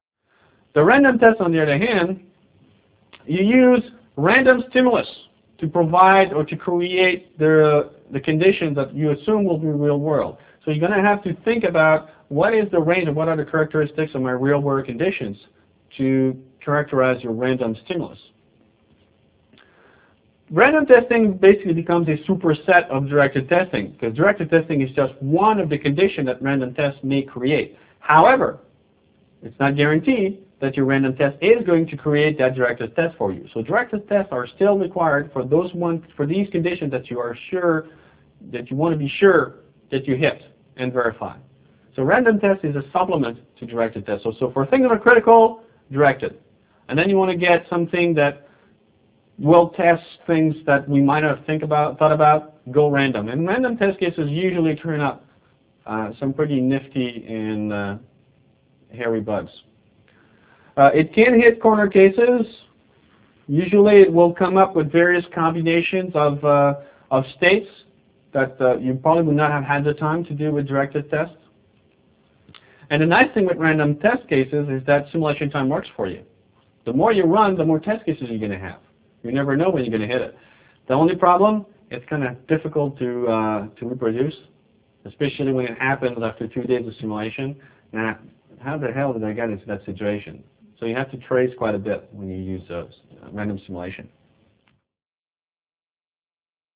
Online Seminar: A Strategic Process for System Level Verification, Slide 38 of 44